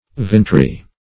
Search Result for " vintry" : The Collaborative International Dictionary of English v.0.48: Vintry \Vint"ry\, n. [OE. viniterie, from OF. vinotier, vinetier, wine merchant.